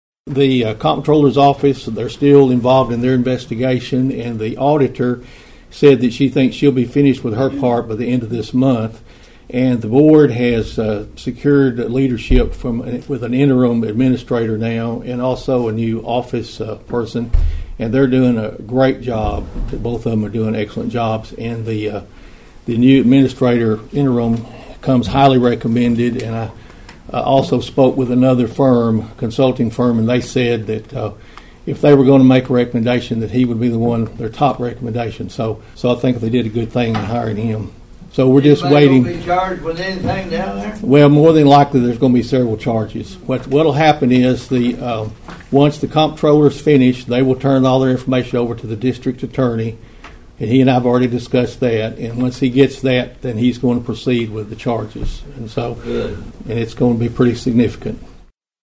Mayor Carr addressed the Obion County Nursing Home during Monday’s monthly meeting.(AUDIO)